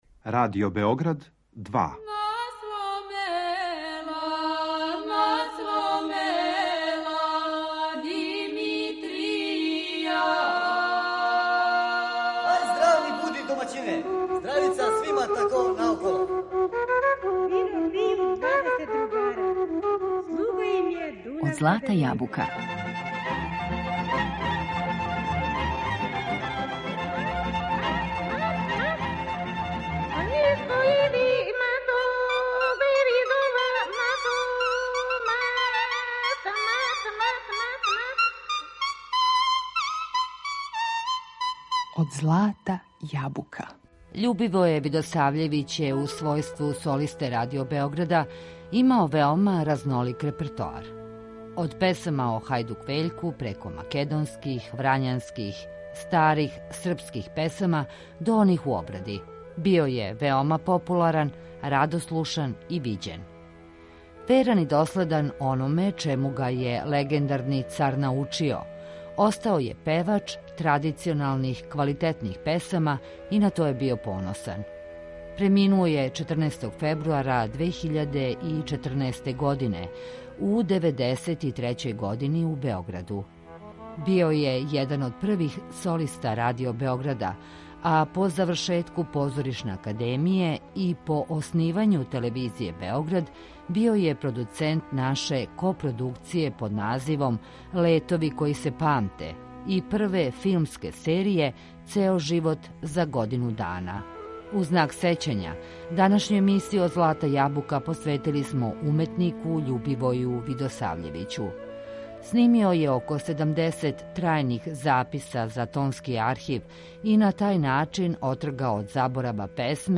Od pesama o Hajduk Veljku, preko makedonskih, vranjanskih, starih srpskih pesama, do onih u obradi. Snimio je više od sedamdeset trajnih zapisa za Tonski arhiv i na taj način otrgao od zaborava mnoge lepe melodije.